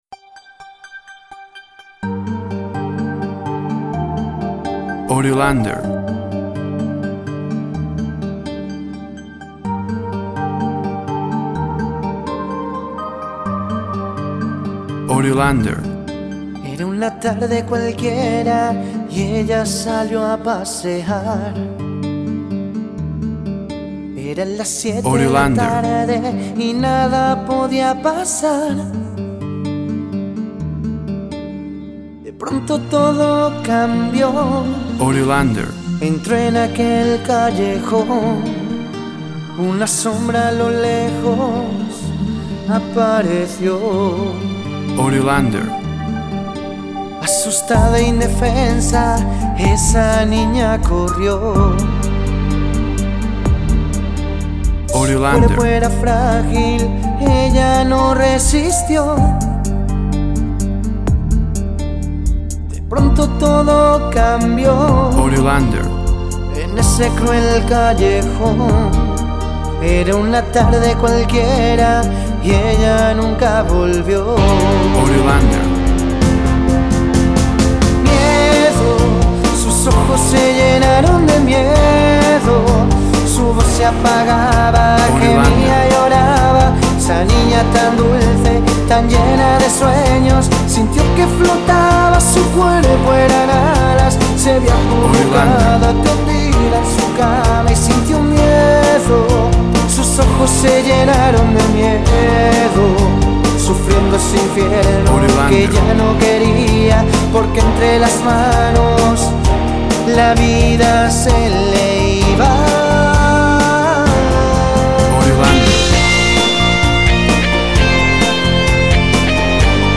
Pop song based on gender violence.
Tempo (BPM) 63